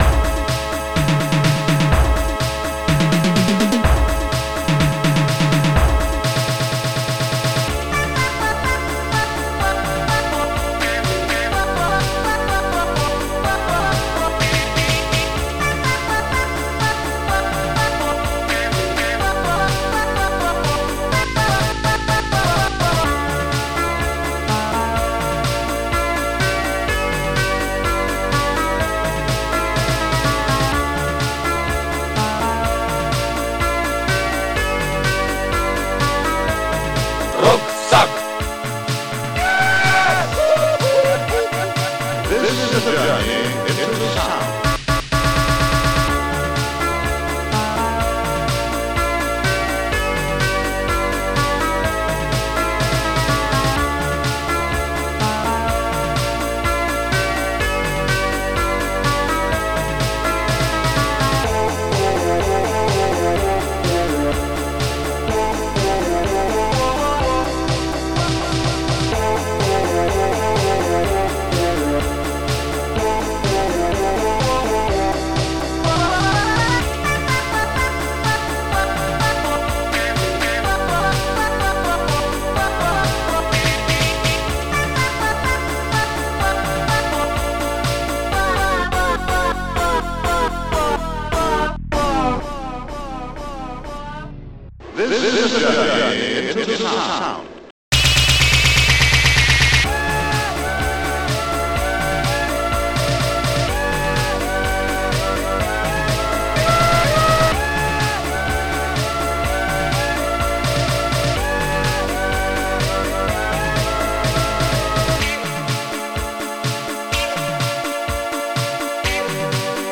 st-01:monobass
st-01:popsnare2
st-01:bassdrum2